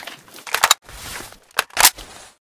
ak74m_reload.ogg